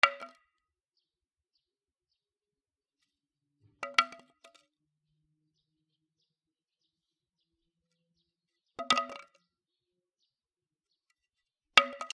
木の棒を転がす
/ M｜他分類 / L01 ｜小道具 /
『コロ コロン』